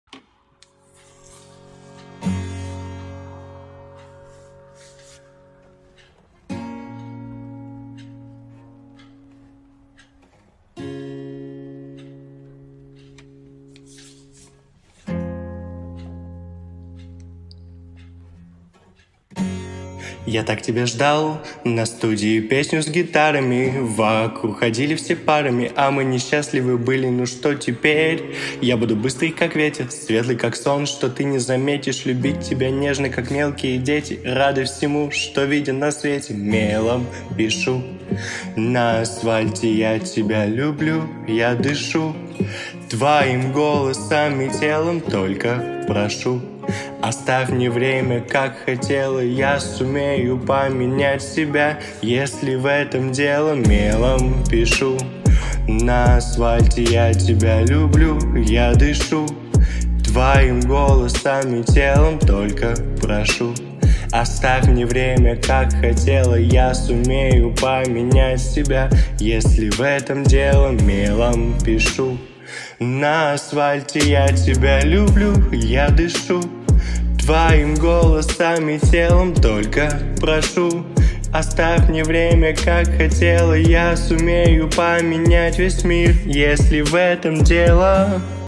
Вокал
Баритон